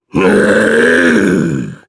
voices / heroes / jp
Phillop-Vox_Casting3_jp.wav